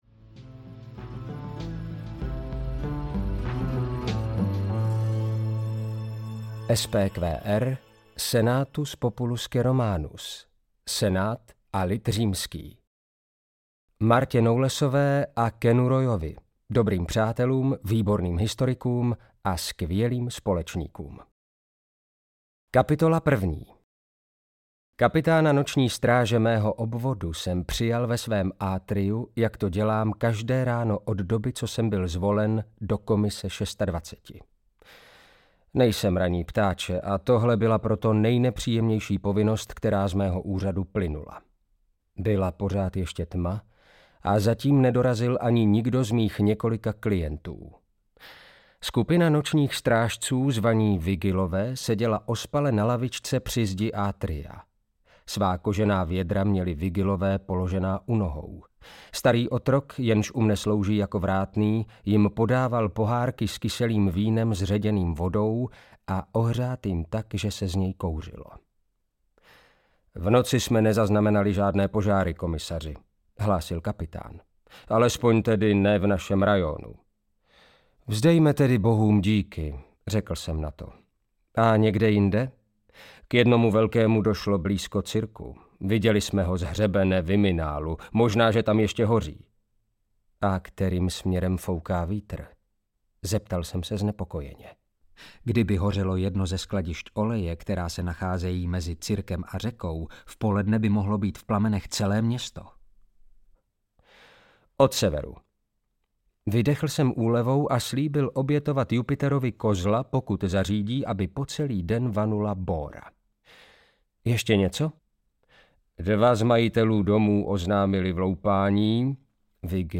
Královský gambit (SPQR I) audiokniha
Ukázka z knihy